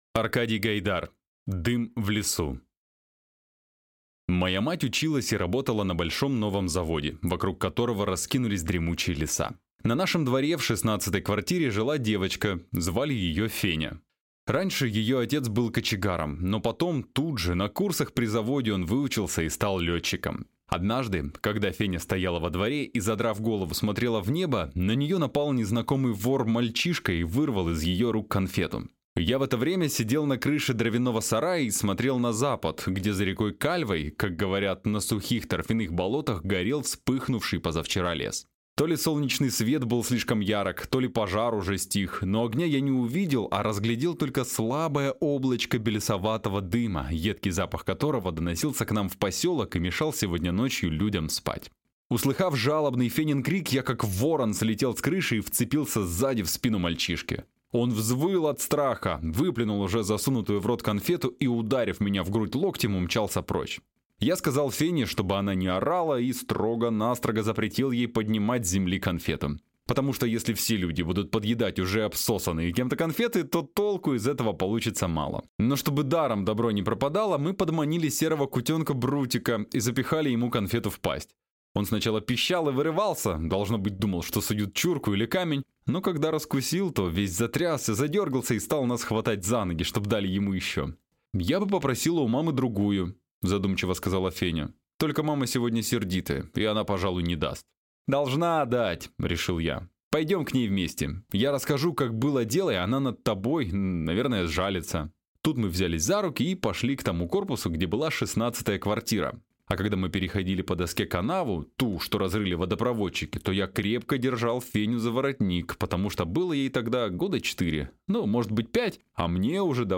Аудиокнига Дым в лесу | Библиотека аудиокниг